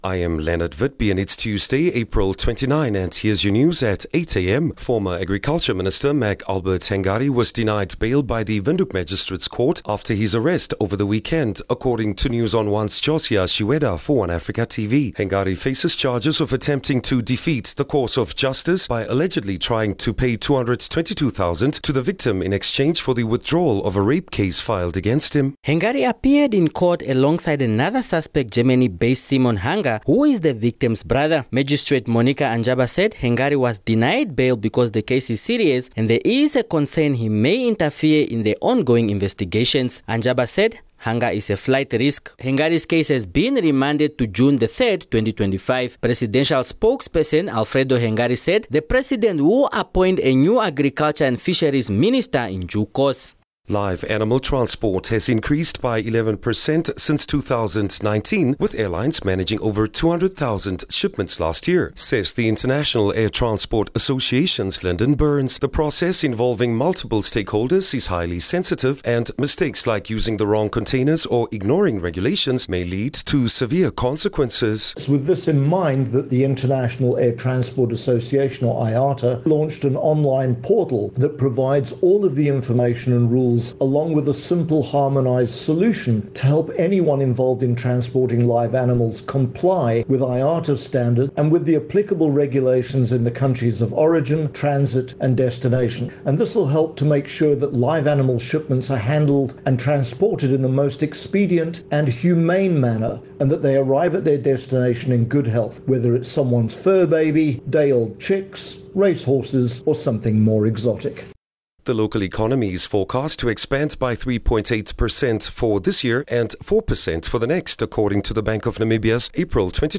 Daily bulletins from Namibia's award winning news team.